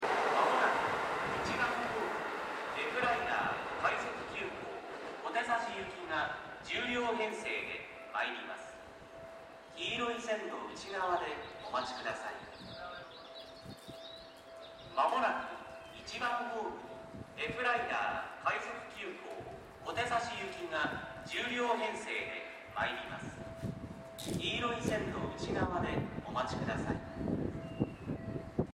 この駅では接近放送が使用されています。
接近放送Fライナー　快速急行　小手指行き接近放送です。